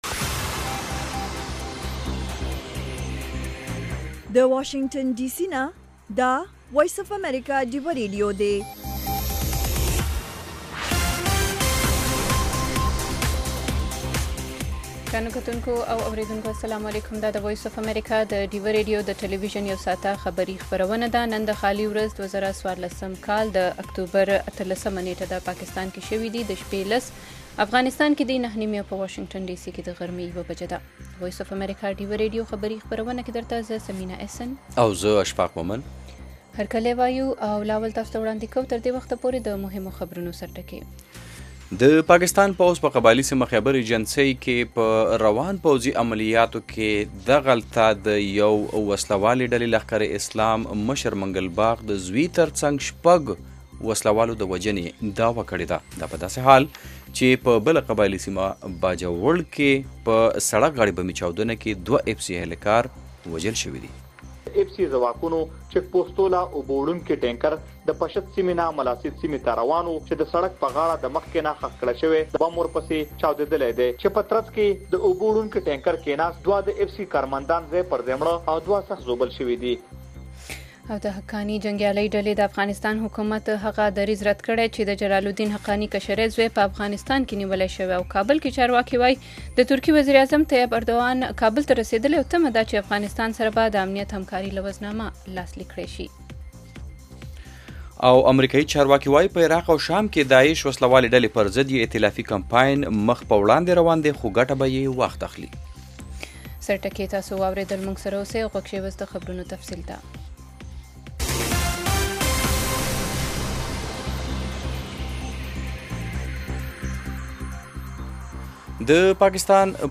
خبرونه - 1700
د وی او اې ډيوه راډيو ماښامنۍ خبرونه چالان کړئ اؤ د ورځې د مهمو تازه خبرونو سرليکونه واورئ.